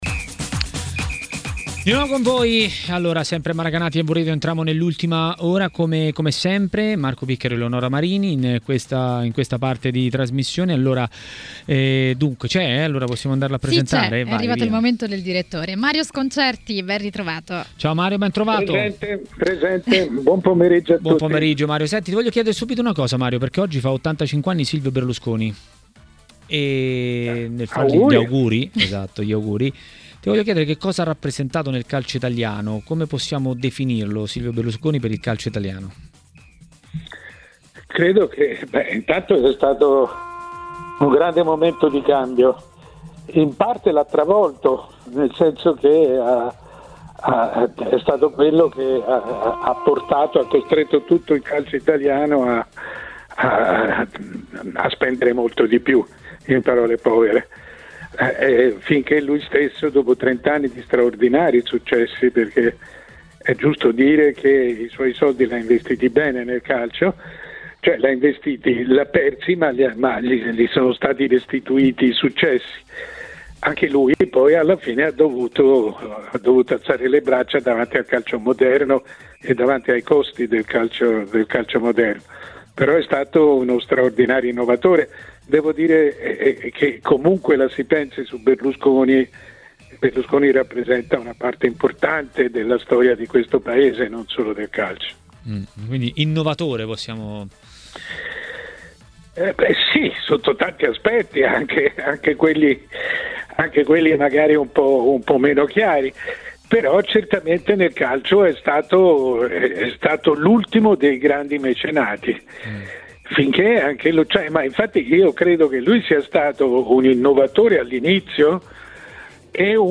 Il direttore Mario Sconcerti a Maracanà, nel pomeriggio di TMW Radio, ha parlato dei temi di giornata.